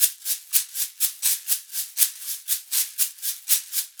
Index of /90_sSampleCDs/USB Soundscan vol.36 - Percussion Loops [AKAI] 1CD/Partition A/02-60SHAKERS
60 SHAK 02.wav